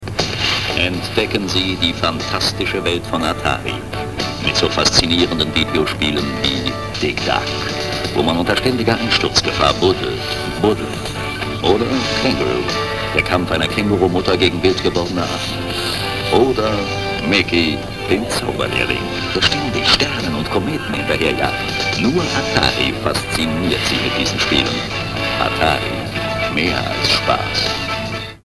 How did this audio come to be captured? Two great audio commercials from German TV.